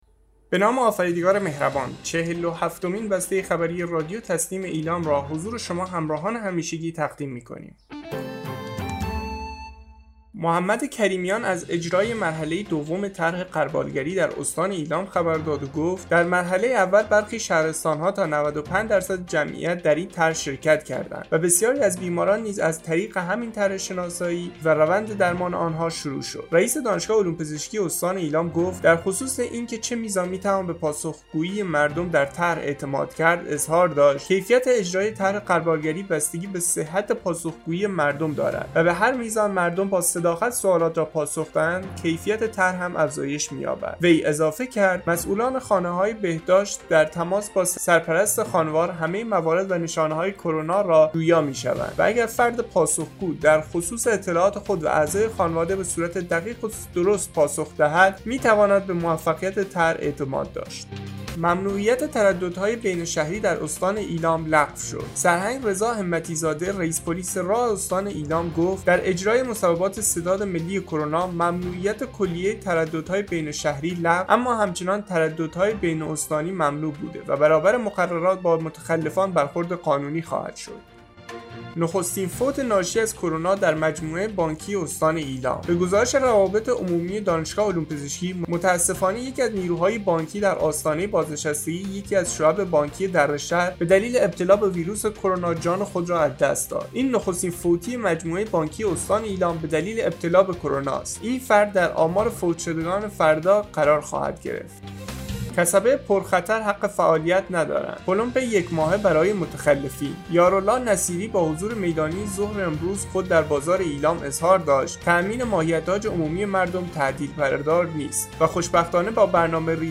به گزارش خبرگزاری تسنیم از ایلام, چهل و هفتمین بسته خبری رادیو تسنیم استان ایلام با خبرهایی چون کسبه پرخطر حق فعالیت ندارد/ پلمب یک ماهه برای متخلفین،95 درصد مردم ایلام در مرحله دوم طرح غربالگیری شرکت کرده‌اند،ممنوعیت ترددهای بین‌شهری در استان ایلام لغو شدونخستین فوت ناشی از کرونا در مجموعه بانکی استان ایلام منتشر شد.